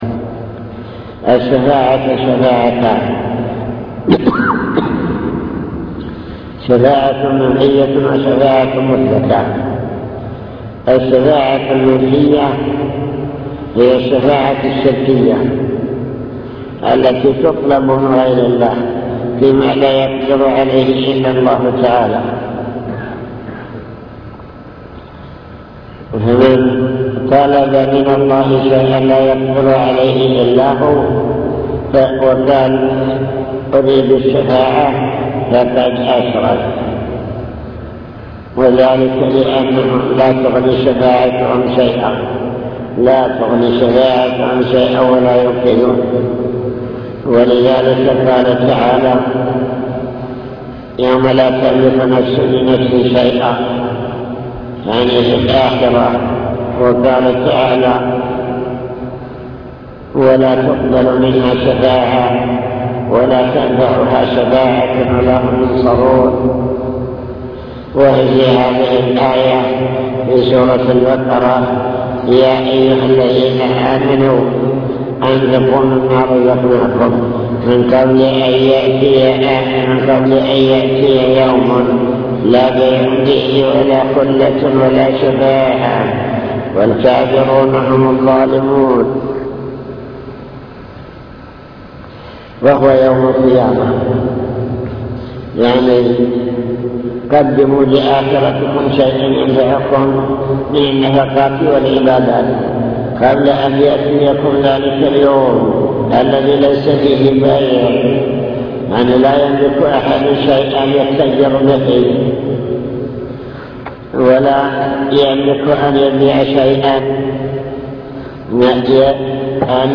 المكتبة الصوتية  تسجيلات - محاضرات ودروس  درس الفجر - القواعد الأربعة